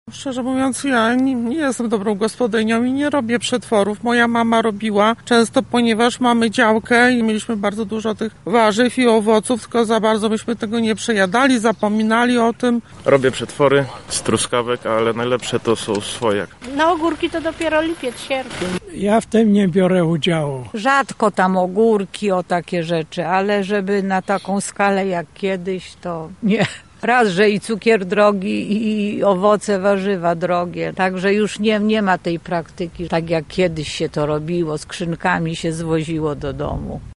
Zapytaliśmy mieszkańców Lublina, czy szykują już słoiki, w których zapasteryzują owocowe i warzywne przysmaki na zimę:
Sonda